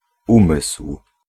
Ääntäminen
IPA : /maɪnd/